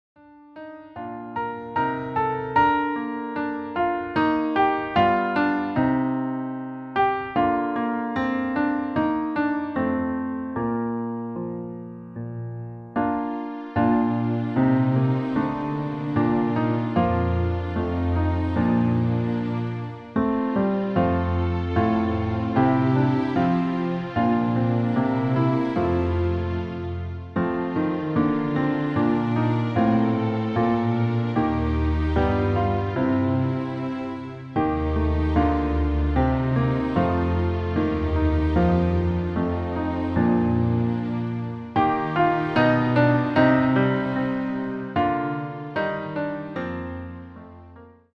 Bb Dur Klavierversion
• Tonart: Bb Dur
• Art: Klavier Streicher
• Das Instrumental beinhaltet keine Leadstimme
Lediglich die Demos sind mit einem Fade-In/Out versehen.
Klavier / Streicher